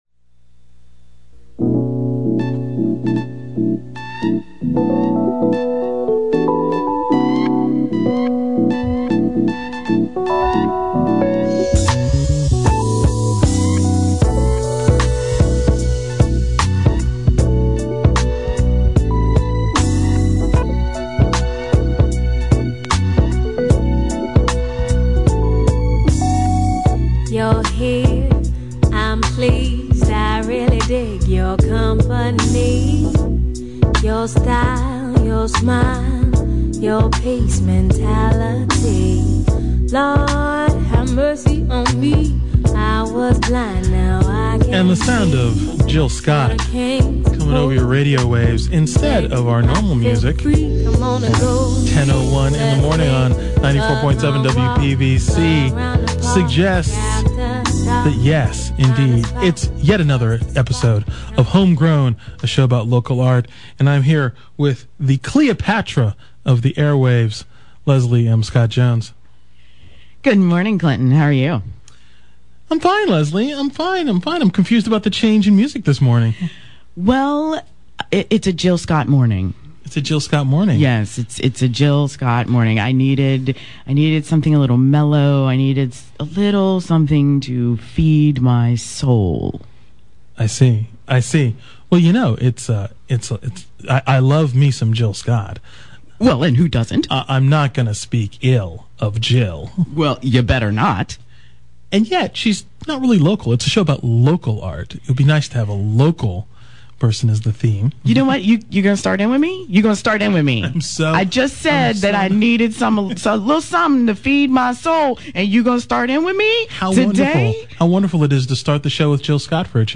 Home Grown is heard on WPVC 94.7 Sunday mornings at 10:00 a.m.